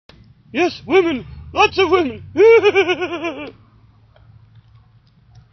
My voice is some what tight and horse. But that's Peter Seller's line from Dr. Strangelove or: How I Learned to Stop Worrying and Love the Bomb.